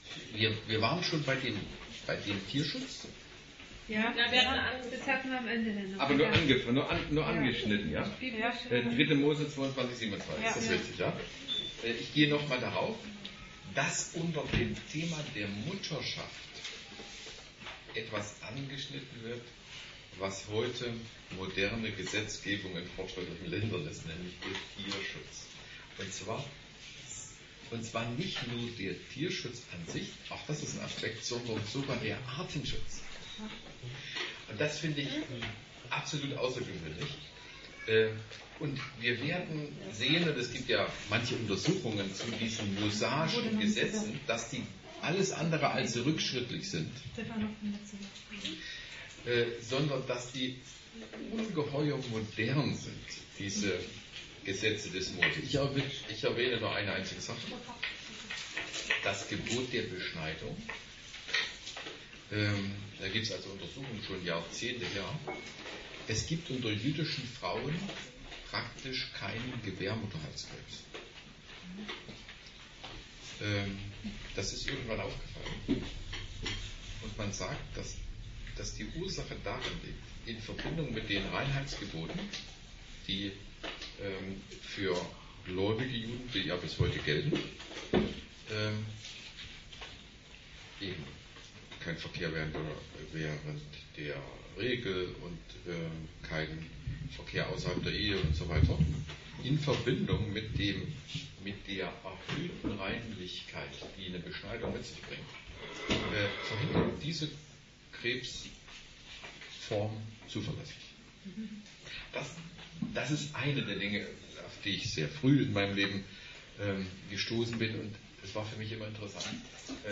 Predigt